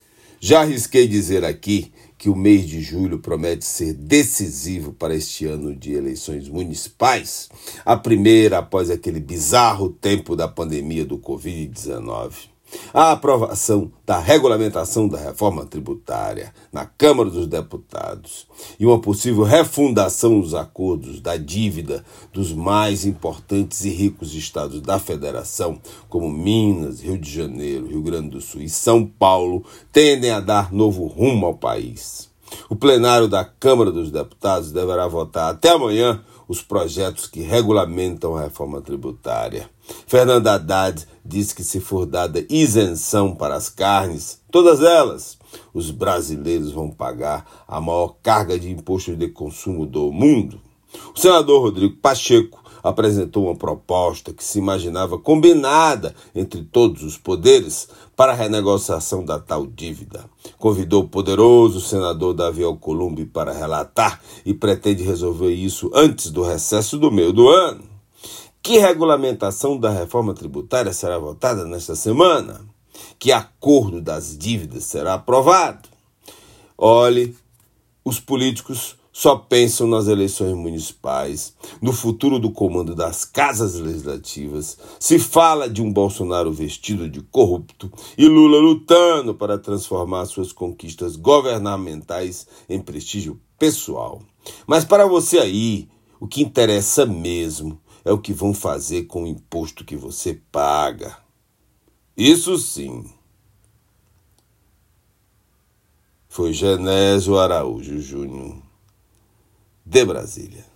Comentário desta quarta-feira (10/07/24)
direto de Brasília.